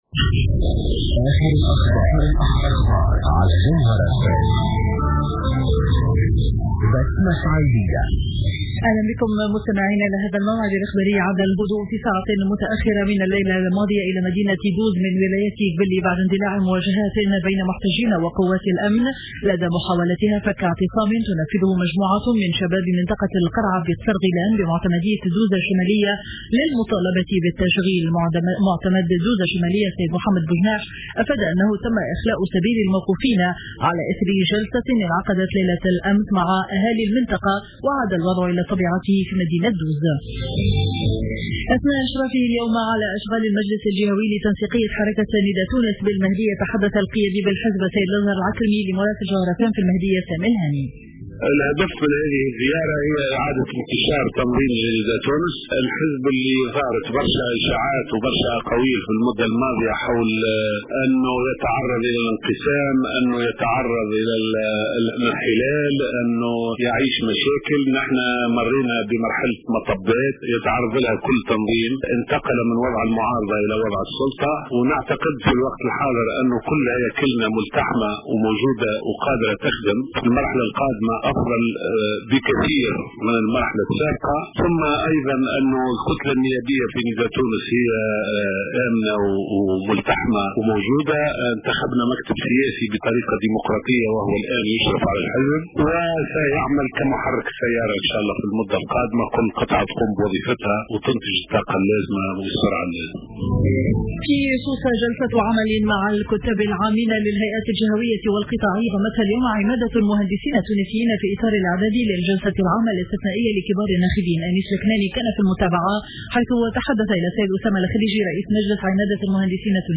نشرة أخبار منتصف النهار ليوم الأحد 05 أفريل 2015